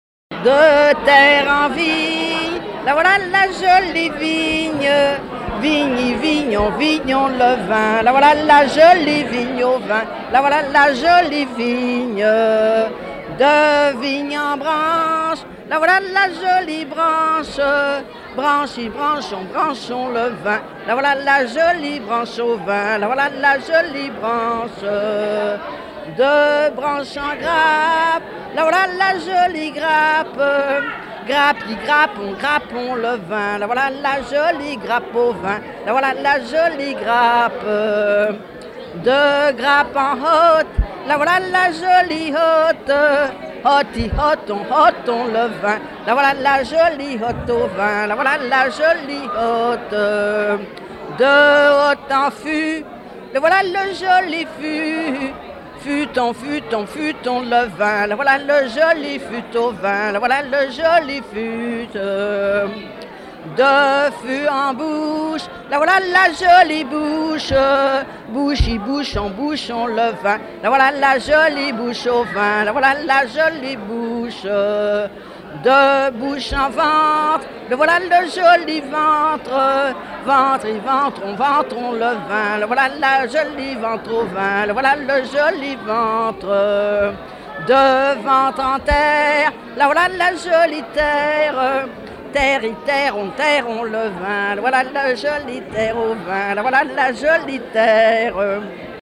regroupement de chanteurs
Genre énumérative
Veillée de chanteurs
Pièce musicale inédite